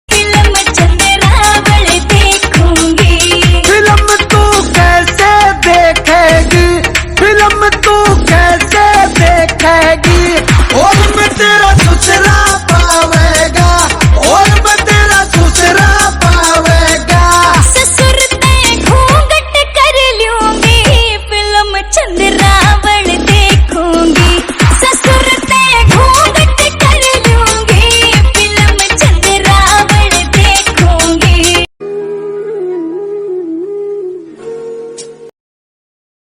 Haryanvi Song Ringtone